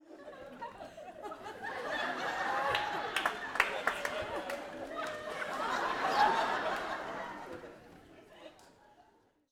Audience Laughing-01.wav